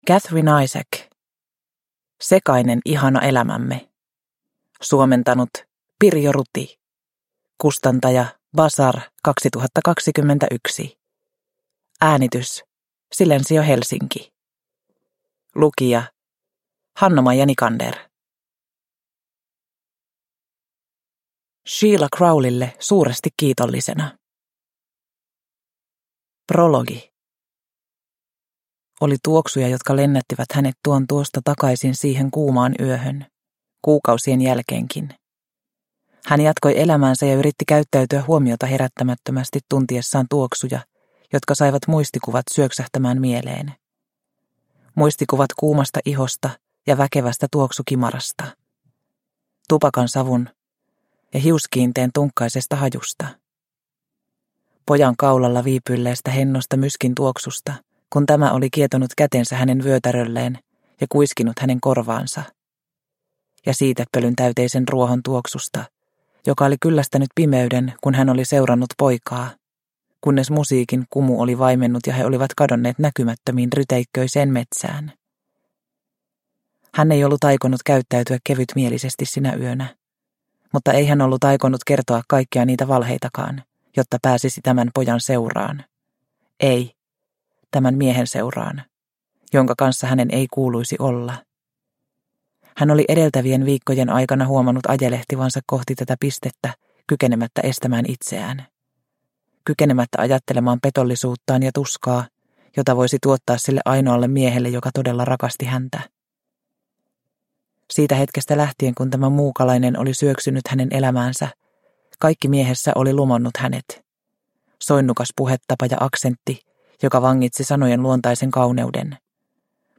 Sekainen ihana elämämme – Ljudbok – Laddas ner